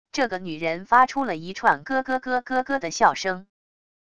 这个女人发出了一串咯咯咯咯咯的笑声wav音频